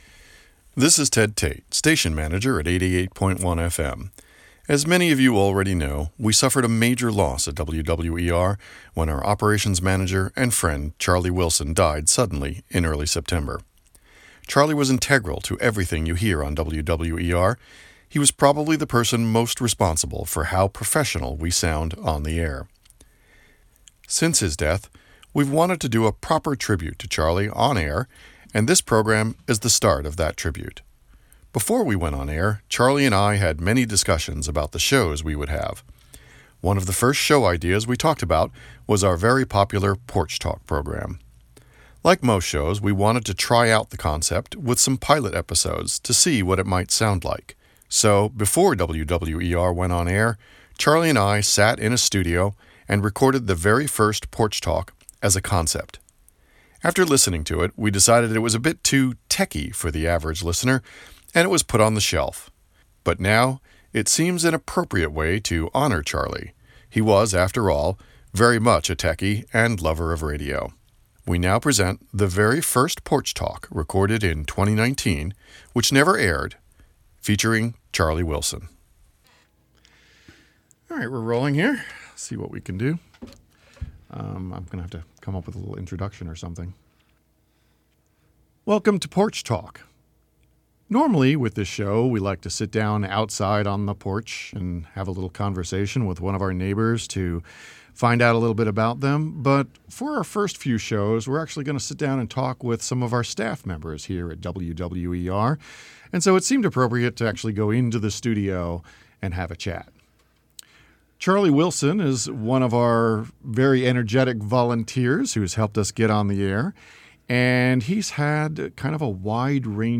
Two radio geeks talking shop…